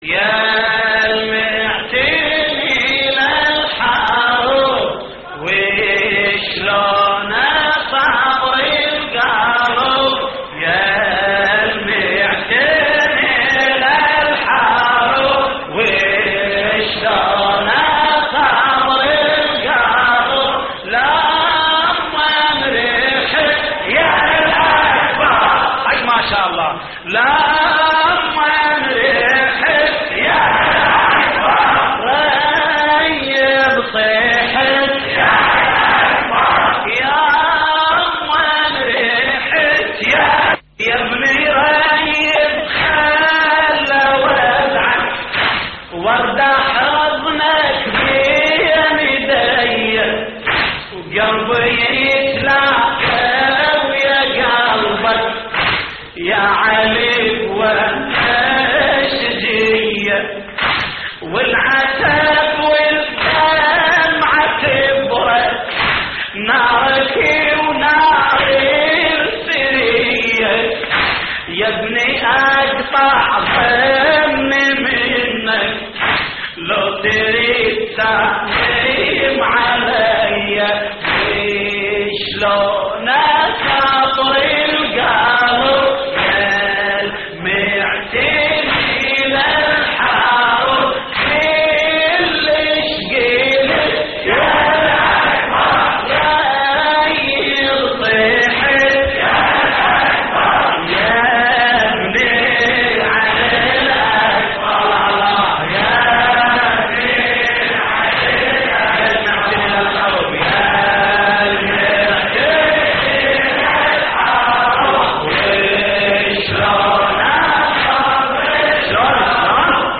تحميل : يالمعتني للحرب وشلون أصبر القلب / الرادود جليل الكربلائي / اللطميات الحسينية / موقع يا حسين